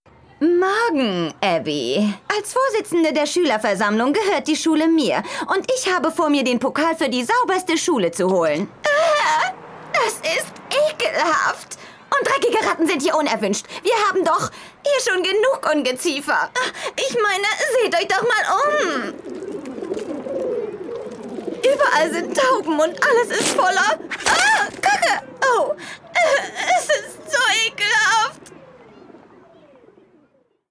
Sprecherin englisch (usa).
norddeutsch
Sprechprobe: eLearning (Muttersprache):